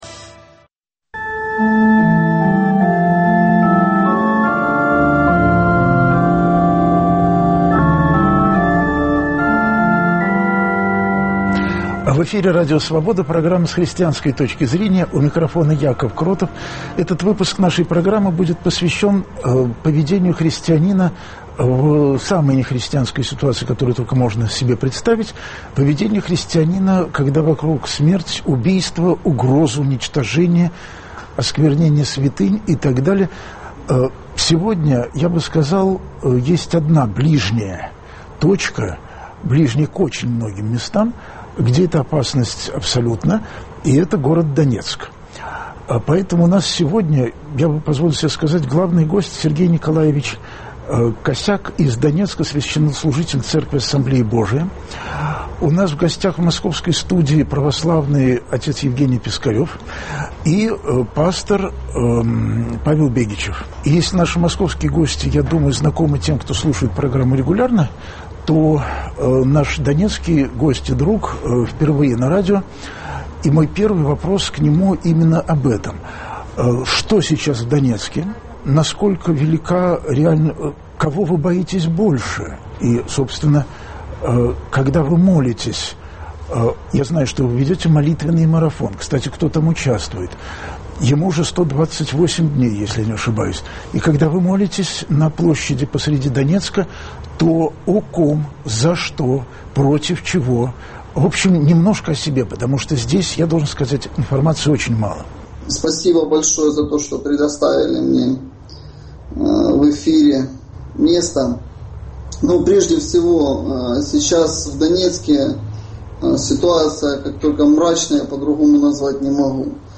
в московской студии